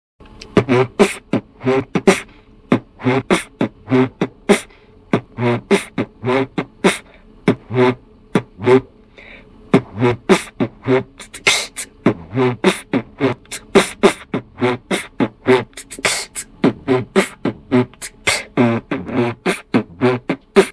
Честно мне нрава))Оч хорошо битбоксишь smile